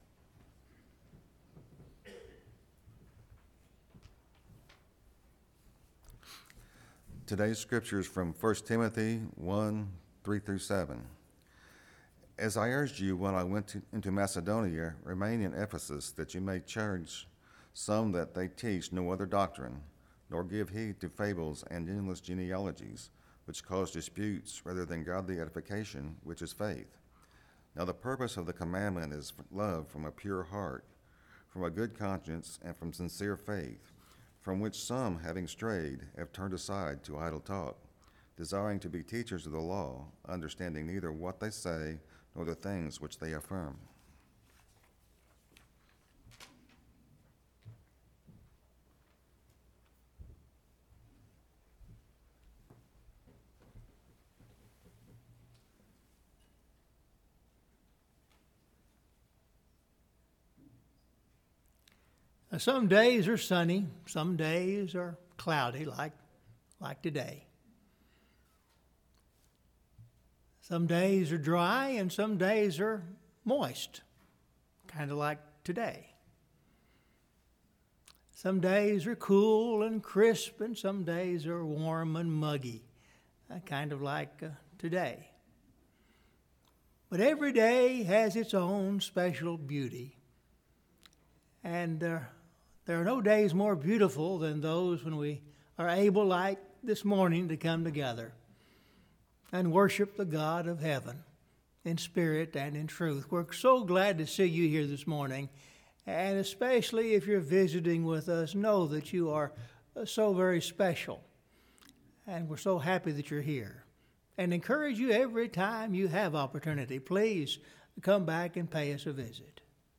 Scripture Reading – 1 Timothy 1:3-7